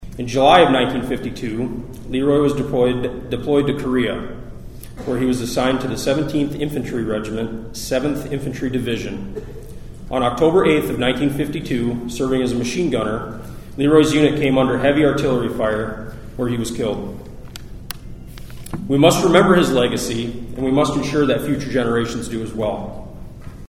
ONIDA, (KCCR) — Students and residents filled the Sully Buttes High School gym Wednesday afternoon to honor a local Fallen Hero by dedicating a bridge in his name. Department of Veteran’s Affairs Deputy Secretary Aaron Pollard says Private LeRoy Goose of Onida joined the Army in 1952…